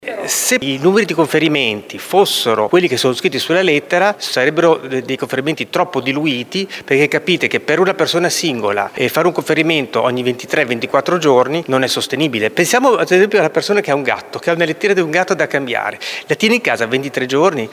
Giovanni Bertoldi, capogruppo Lega…